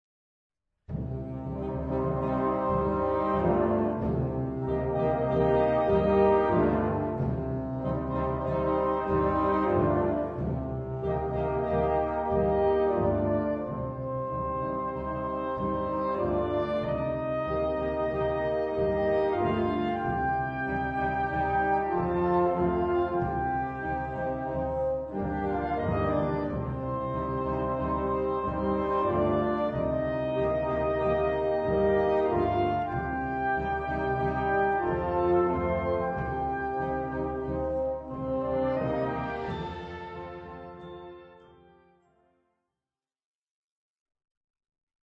Gattung: 4 Part-Ensemble
Besetzung: Blasorchester